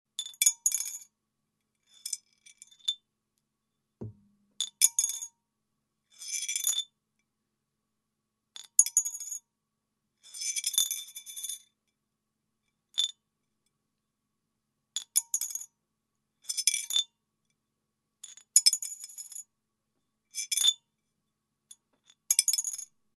На этой странице собраны звуки копилки: от звонкого падения монет до глухого стука накопленных сбережений.
Звон монетки в пустую копилку